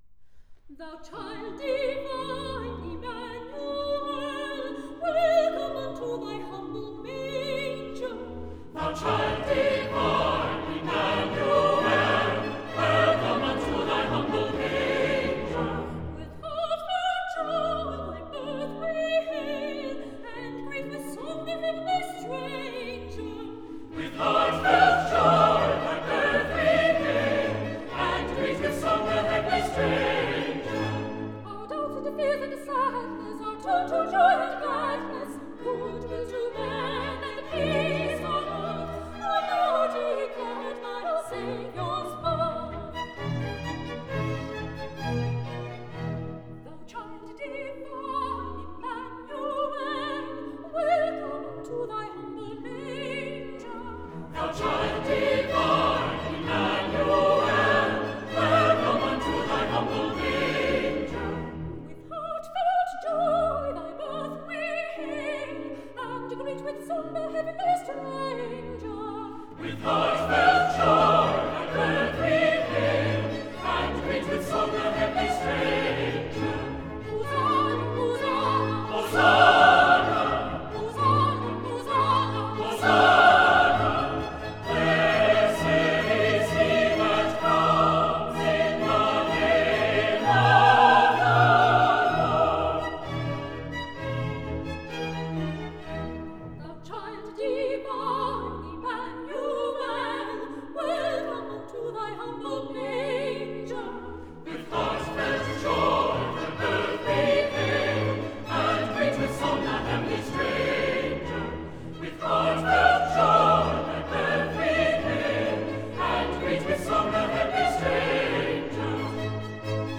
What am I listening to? Anthems During the Service: